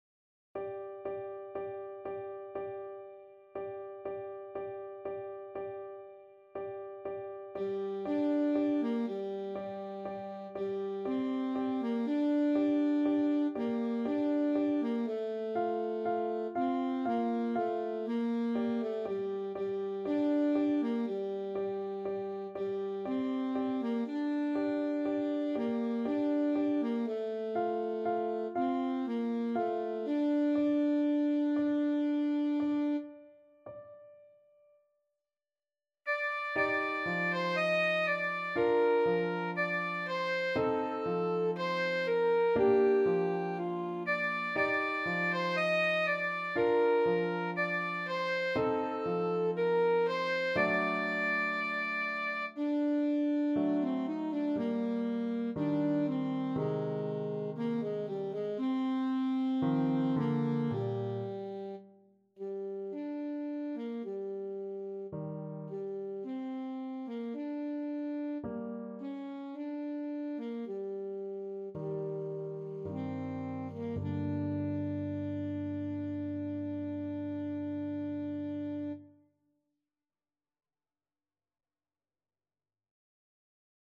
Alto Saxophone
Andante sostenuto =60
3/4 (View more 3/4 Music)
Classical (View more Classical Saxophone Music)